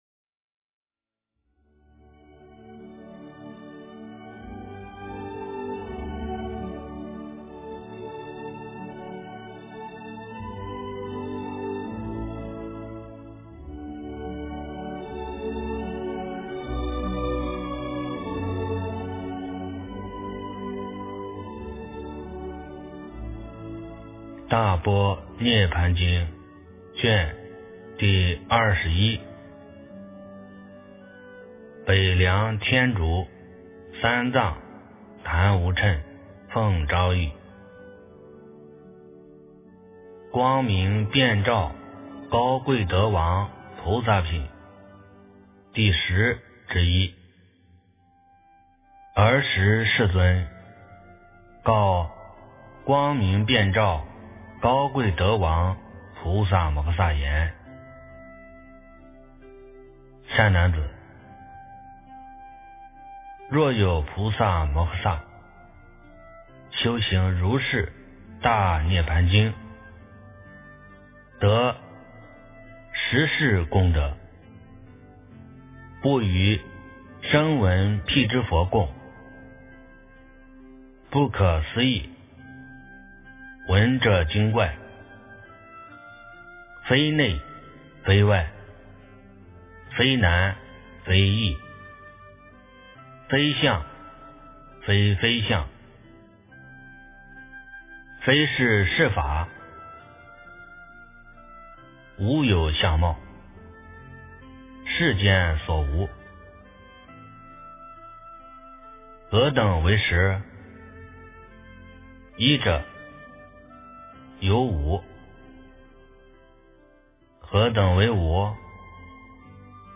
大般涅槃经21 - 诵经 - 云佛论坛